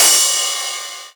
Tuned cymbal samples Free sound effects and audio clips
• Long Room Reverb Ride Cymbal Sound Sample F# Key 01.wav
Royality free drum ride tuned to the F# note. Loudest frequency: 6721Hz
long-room-reverb-ride-cymbal-sound-sample-f-sharp-key-01-DG3.wav